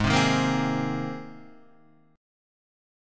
G#add9 chord